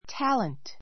talent A2 tǽlənt タ れン ト 名詞 ❶ （生まれながらの） 才能 Ann has a great talent for music [as a musician].